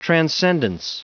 Prononciation du mot transcendence en anglais (fichier audio)
transcendence.wav